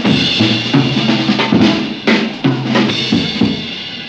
JAZZ BREAK11.wav